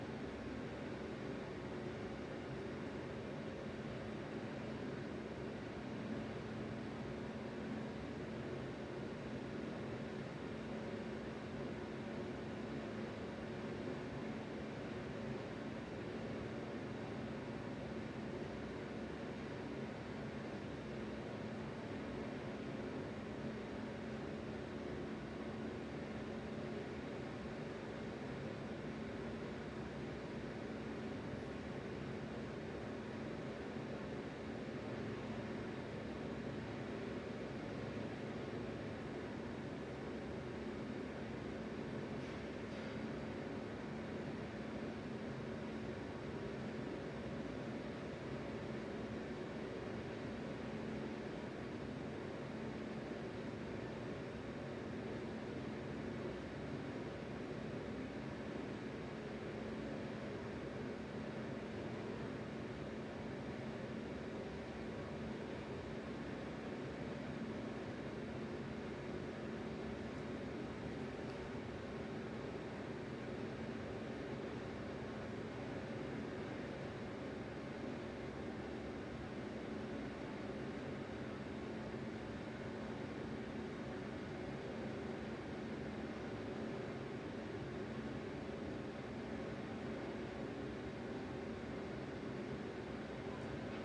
环境 " Elevador Ambience
描述：用ZOOM F4和AT385b录制，用于电影最深的恐惧
Tag: 电梯 roomtone roomtone 房间噪声